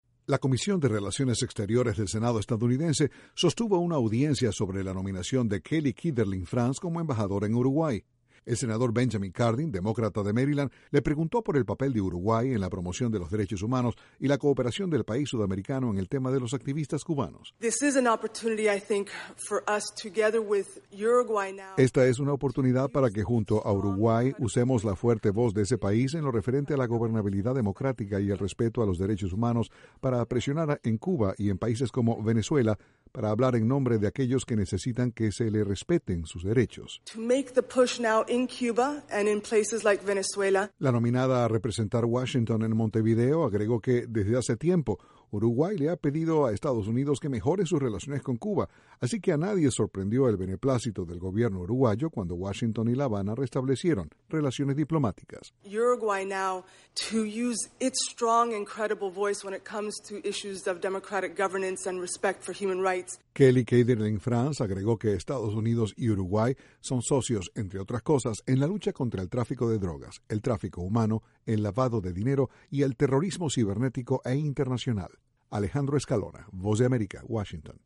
La nominada a representar Estados Unidos en Uruguay se pronunció sobre la defensa a los derechos humanos en Cuba y Venezuela. Desde la Voz de América, Washington, informa